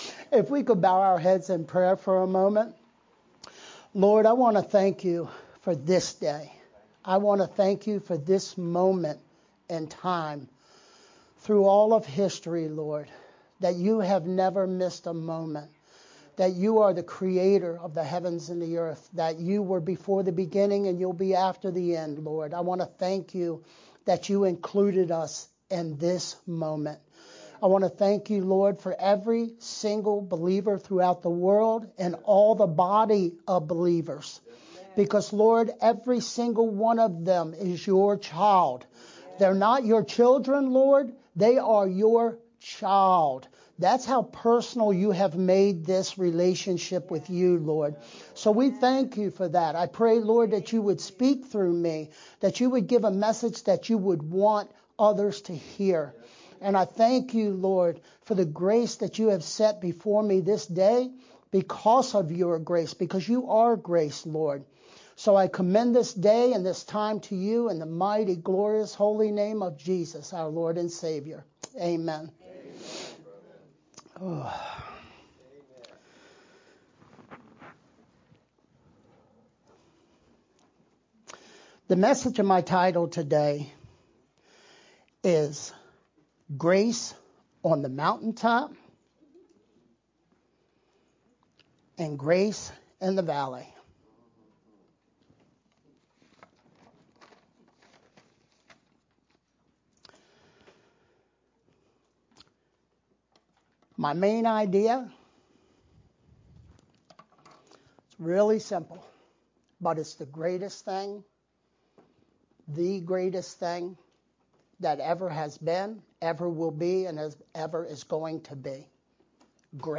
Aug-24th-VBCC-sermon-only-edited_Converted-CD.mp3